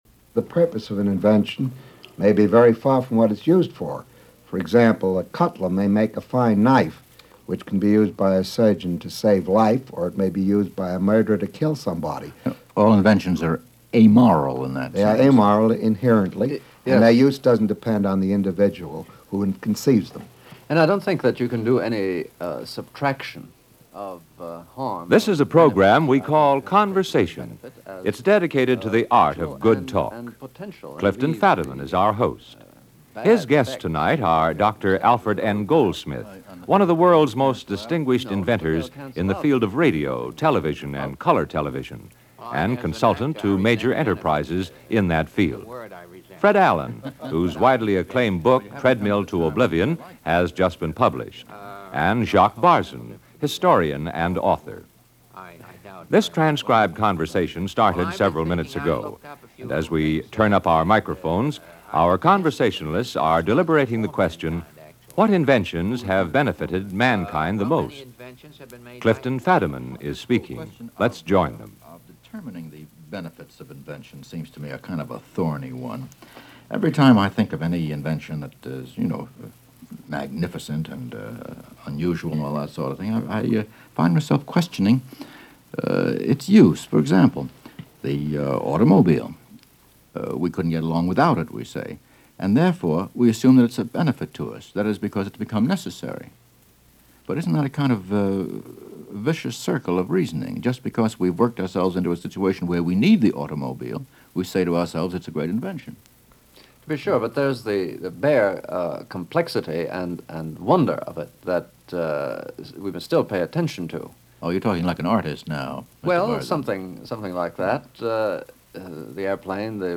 Conversation-What-inventions-have-benefitted-Mankind-the-most-1954.mp3